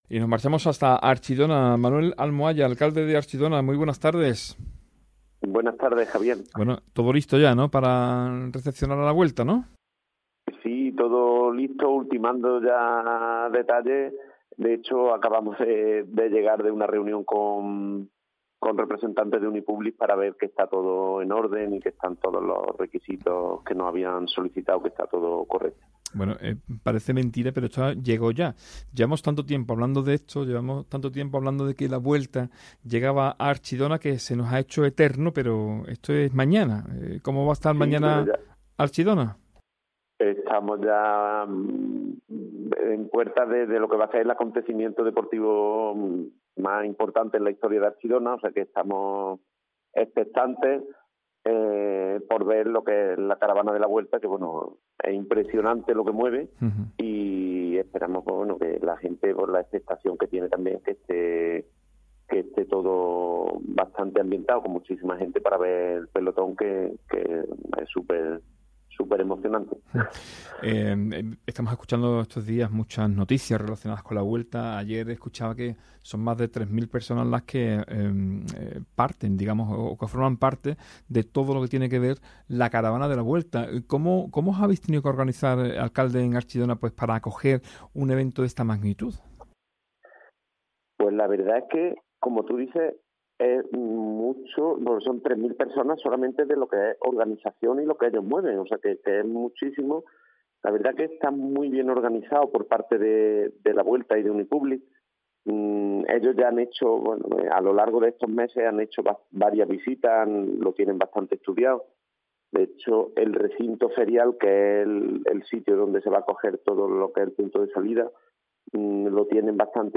ENTREVISTA | Manuel Almohalla (Alcalde de Archidona)